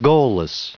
Prononciation du mot goalless en anglais (fichier audio)
goalless.wav